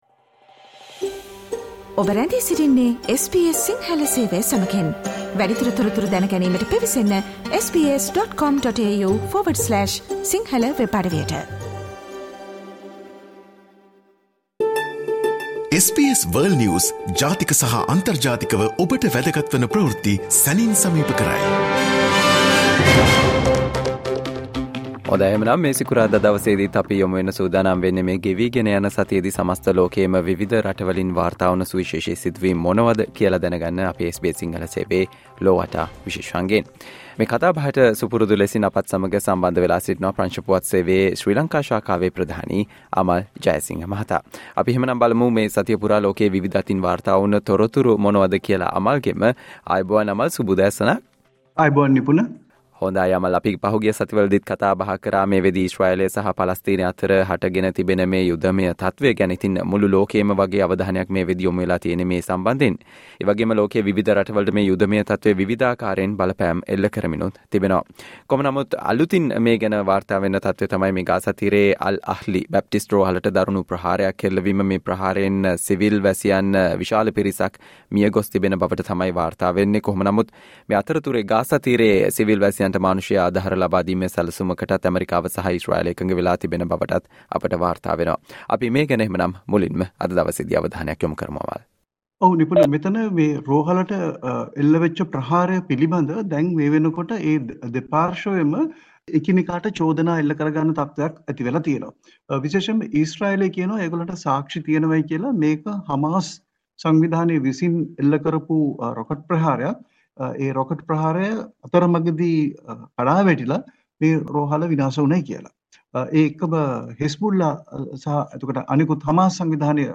and the world news critic World's prominent news highlights in 13 minutes - listen to the SBS Sinhala Radio weekly world News wrap every Friday Share